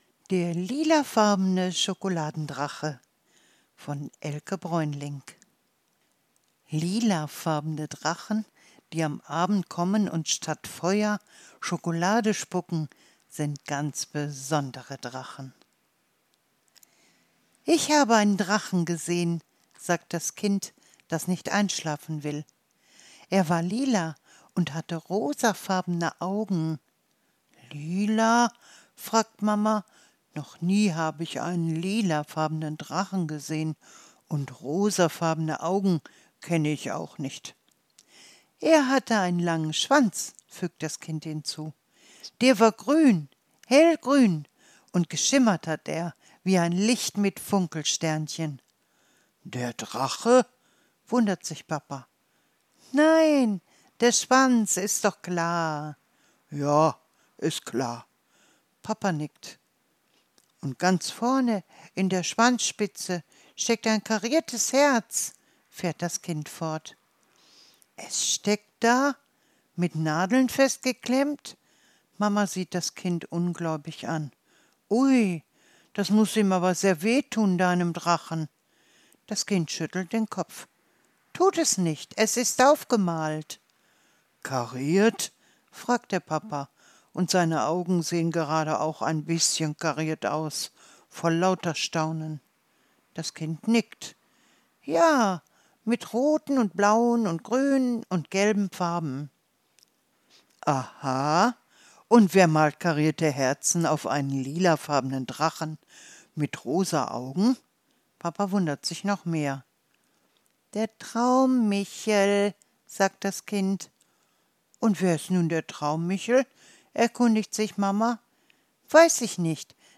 Gutenachtgeschichte – Lilafarbene Drachen, die am Abend kommen und statt Feuer Schokolade spucken, sind ganz besondere Drachen